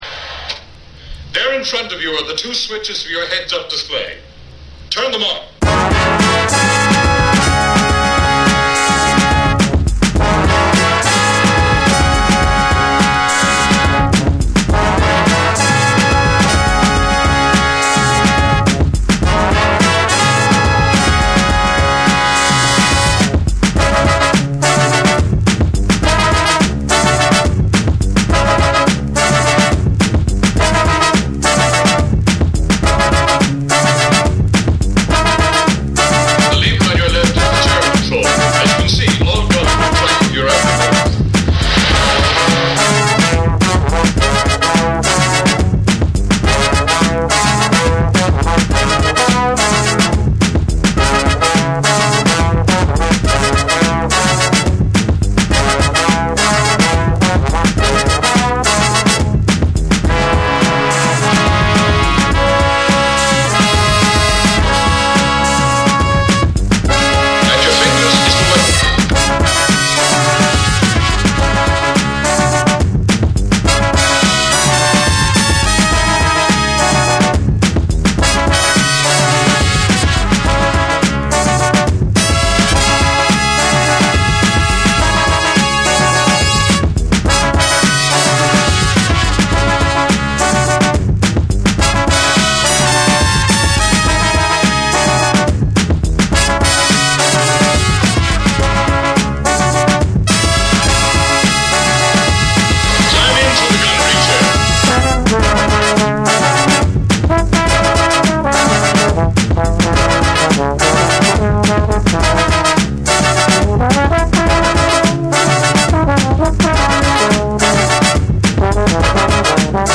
SOUL & FUNK & JAZZ & etc / BREAKBEATS